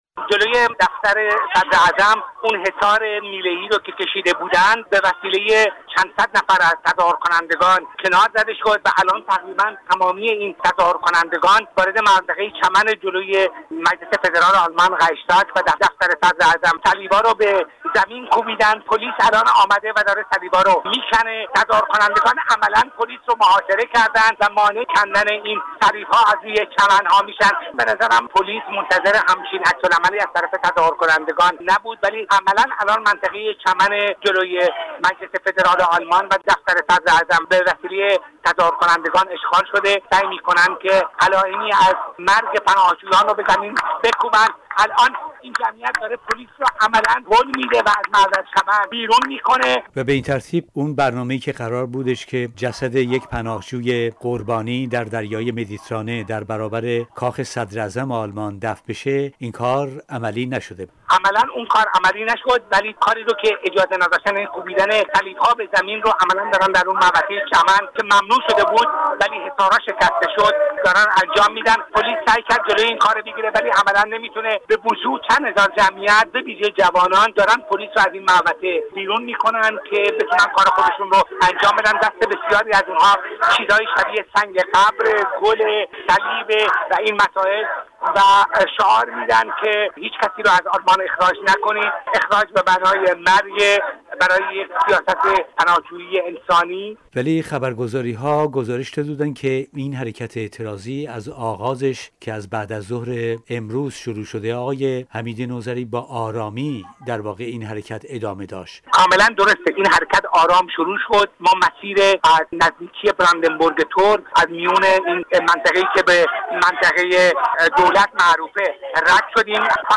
گزارش رادیو فردا از آکسیون مرکز زیبایی های سیاسی